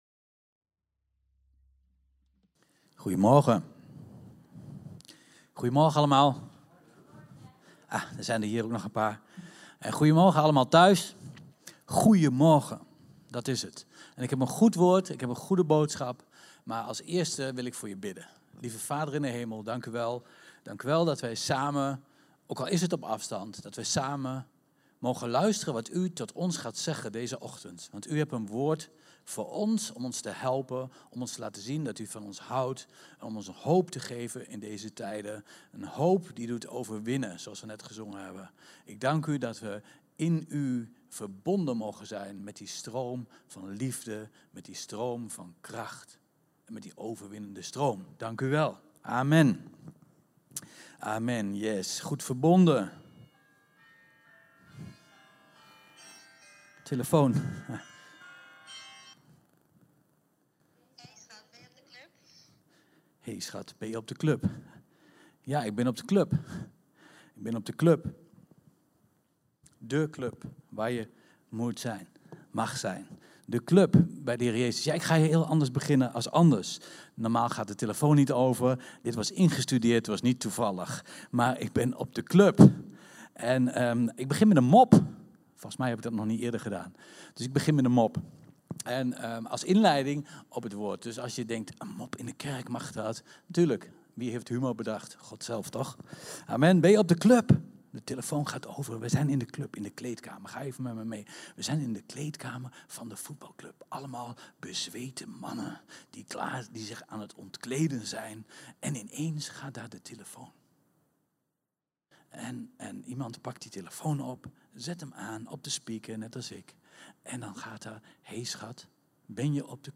Ook wordt er nog een toegift gegeven. Luister hier de preek terug!